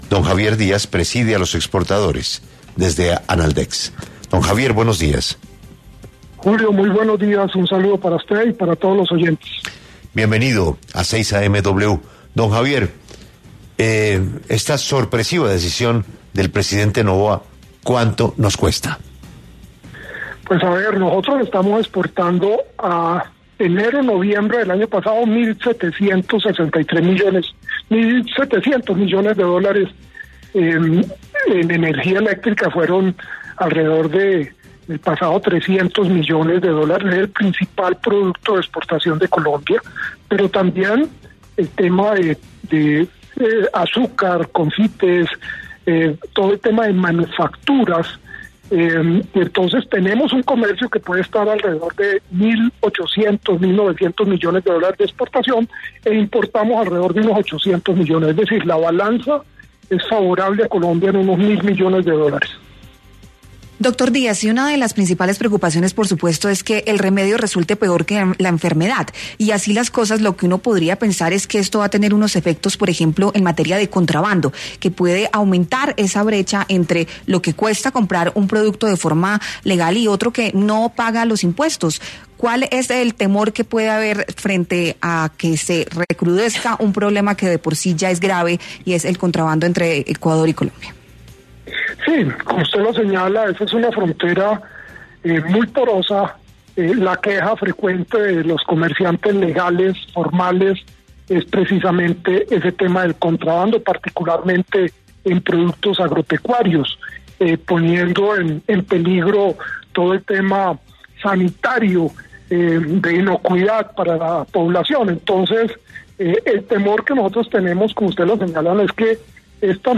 se pronunció en 6AM W de Caracol sobre la guerra comercial entre Colombia y Ecuador, que empezó con la imposición de aranceles de 30 % a los productos colombianos.